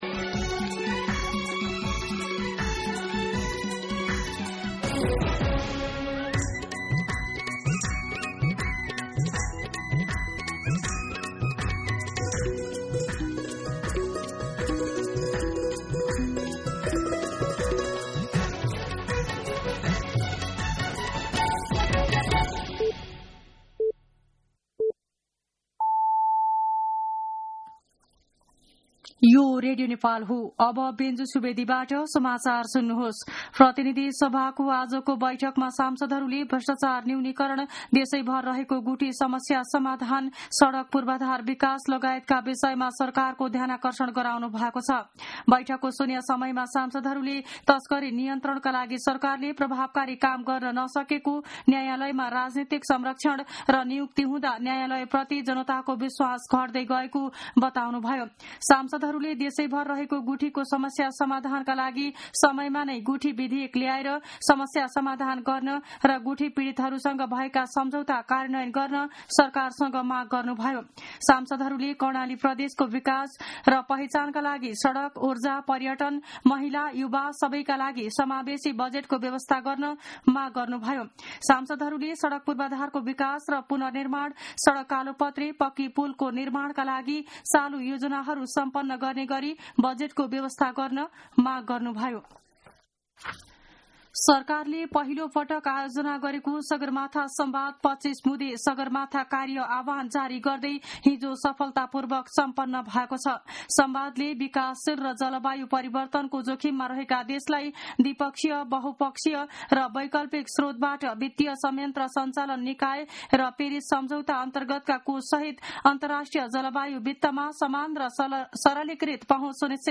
मध्यान्ह १२ बजेको नेपाली समाचार : ५ जेठ , २०८२